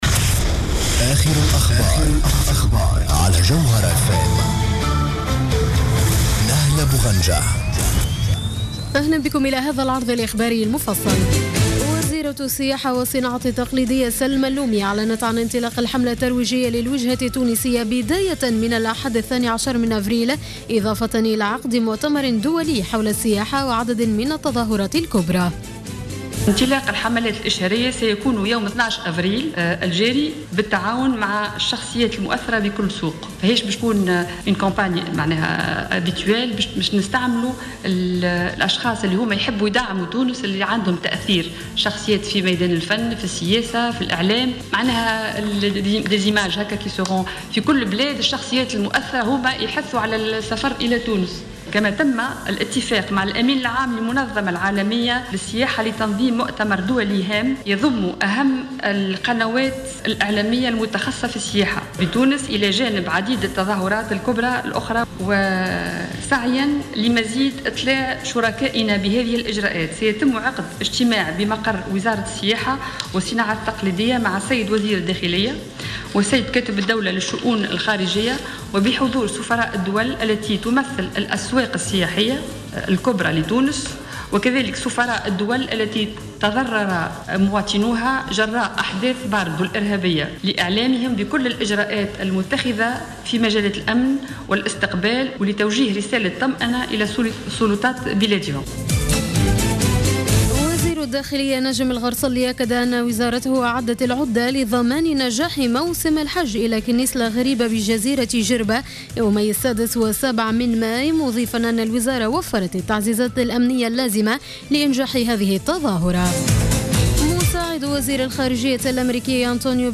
نشرة أخبار منتصف الليل ليوم السبت 11 أفريل 2015